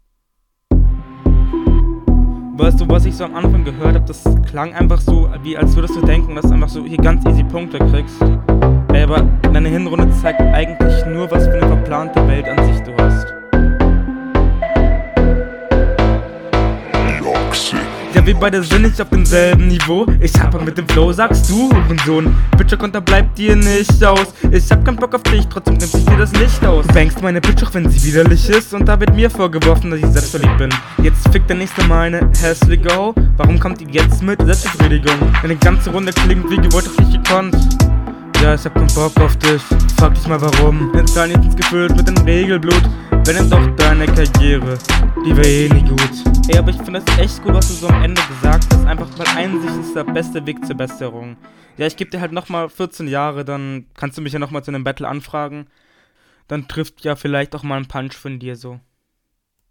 Flowlich deine sicherste Runde, stellenweise noch verhaspelt, schwierig zu …